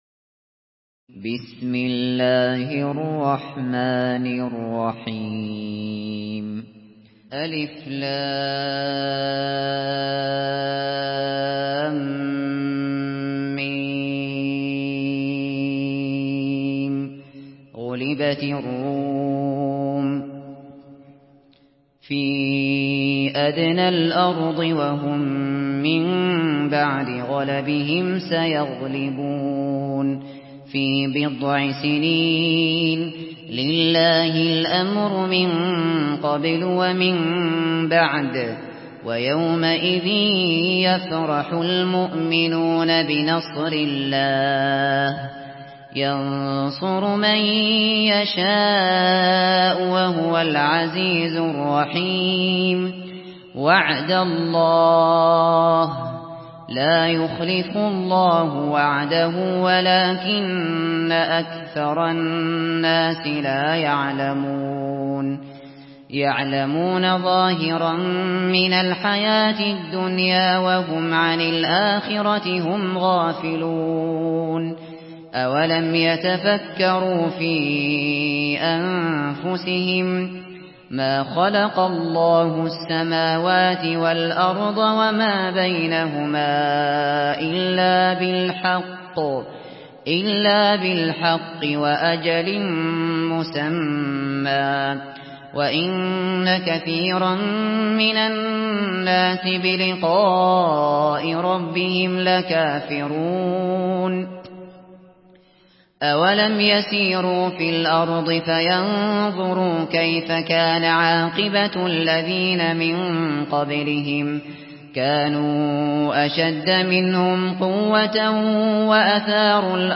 Surah الروم MP3 by أبو بكر الشاطري in حفص عن عاصم narration.
مرتل حفص عن عاصم